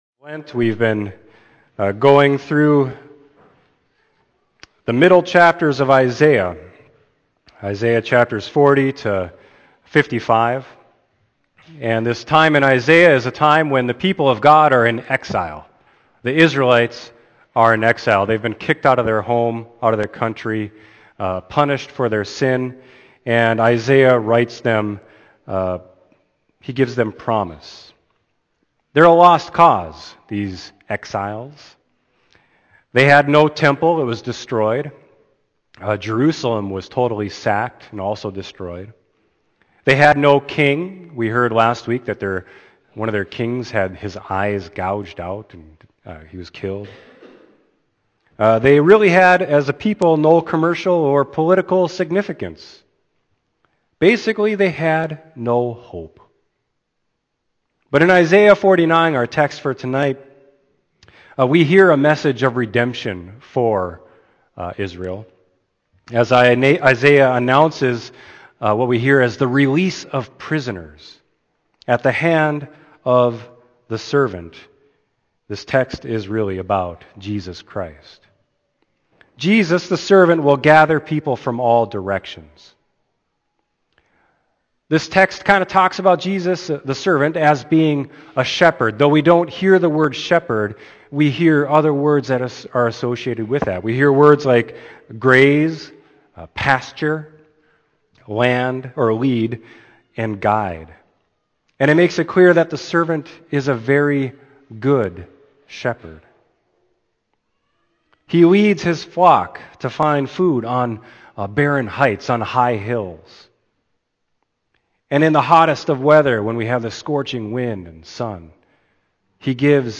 Sermon: Isaiah 49.7-13